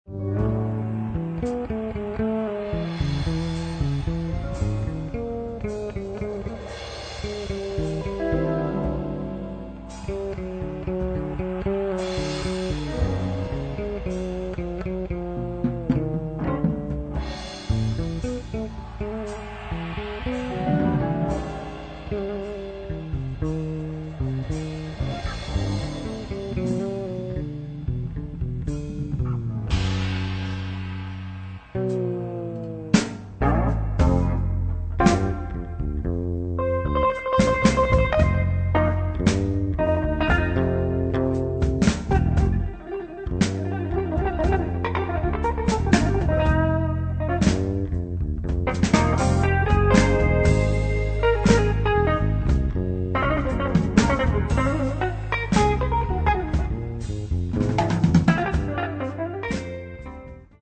in eight funk and latin originals.
Guitar
Fretless Electric Bass
Drums